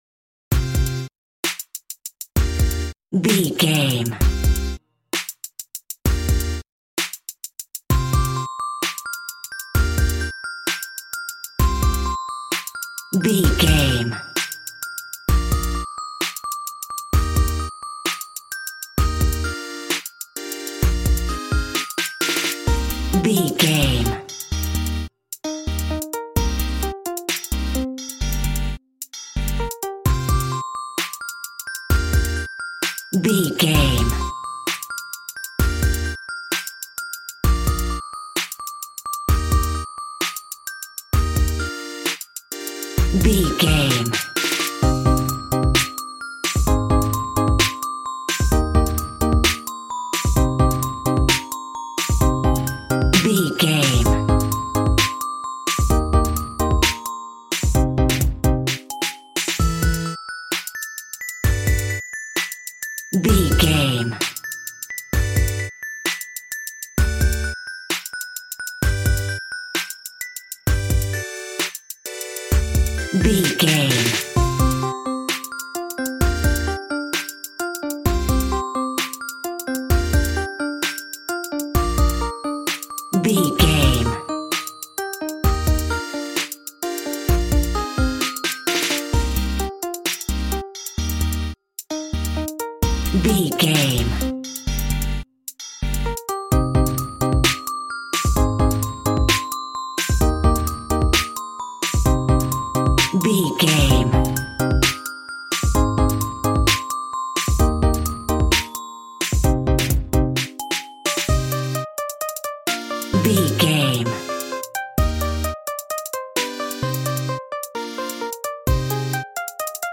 Aeolian/Minor
calm
smooth
synthesiser
piano